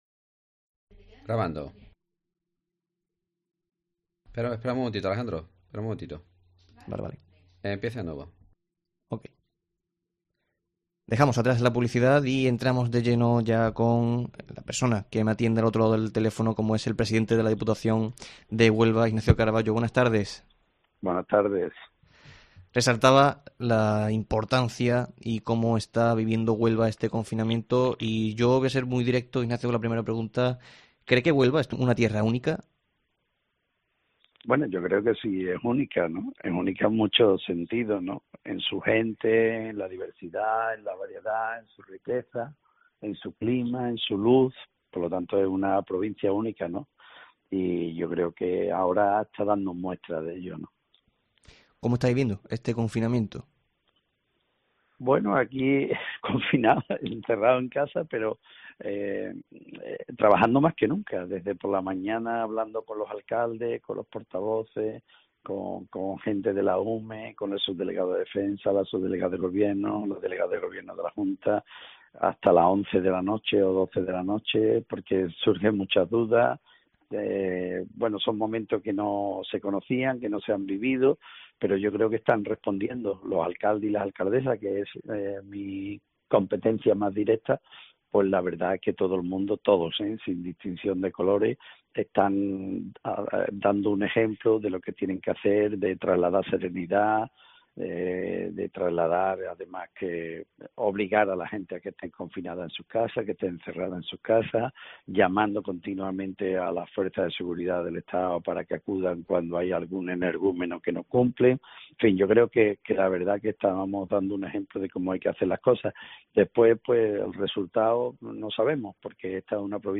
En el tiempo local de Herrera en COPE hablamos con Ignacio Caraballo, presidente de la Diputación, para comentarnos cómo vive este estado de alarma.